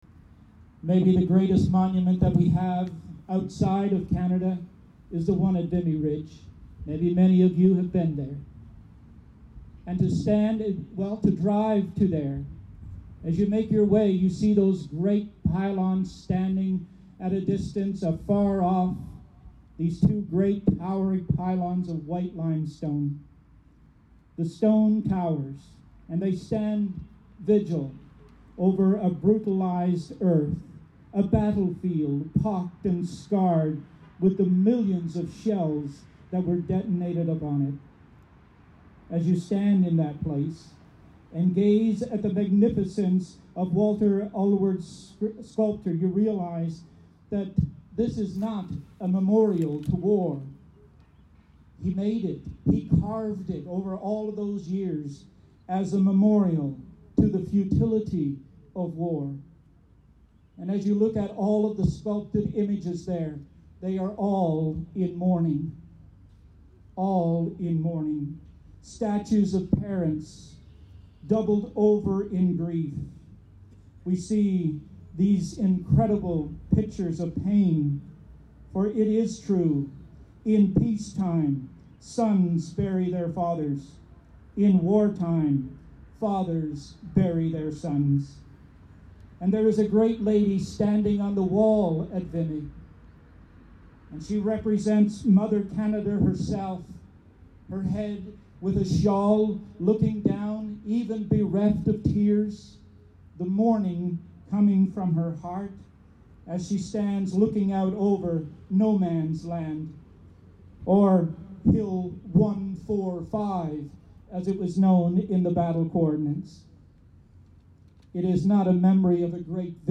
Members of the military, special guests, and residents paused Sunday afternoon at the Belleville Cenotaph to commemorate three pivotal battles and occasions in World Wars I and II.